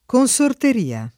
[ kon S orter & a ]